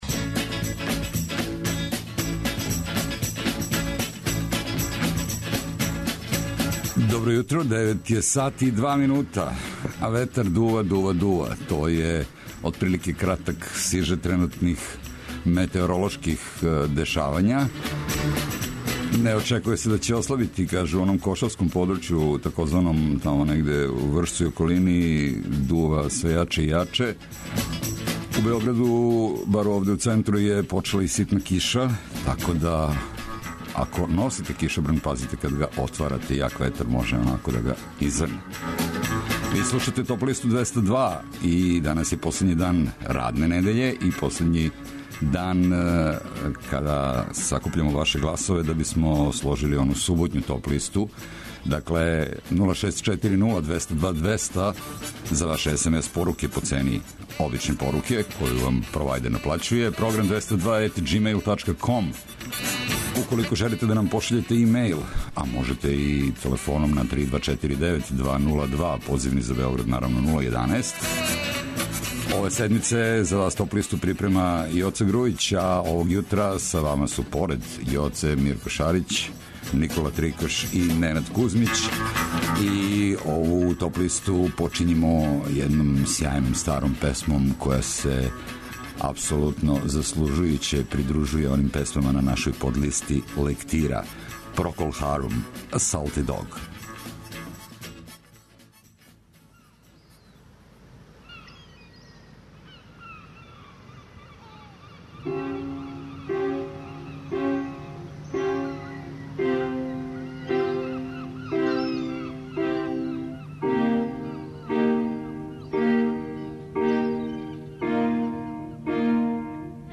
Најавићемо актуелне концерте у овом месецу, подсетићемо се шта се битно десило у историји музике у периоду од 12. до 16. октобра. Ту су и неизбежне подлисте лектире, обрада, домаћег и страног рока, филмске и инструменталне музике, попа, етно музике, блуза и џеза, као и класичне музике.